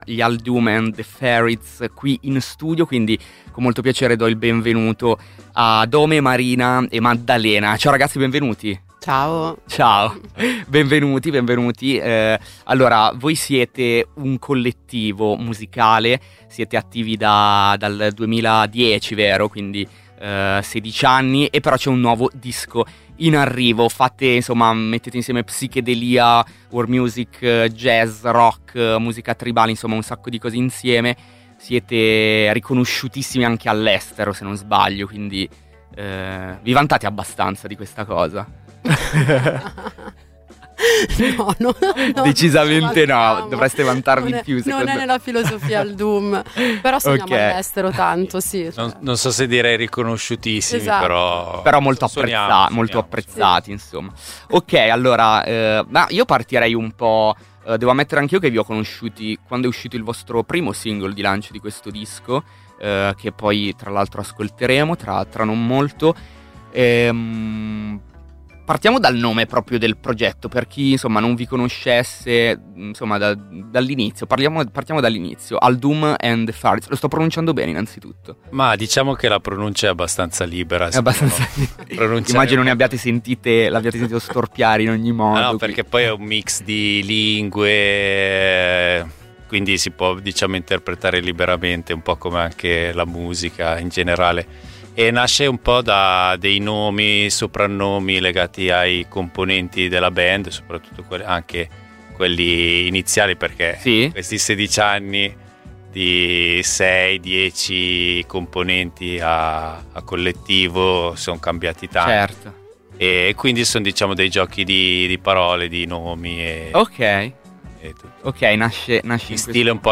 Ascolta l’intervista agli Al Doum & The Faryds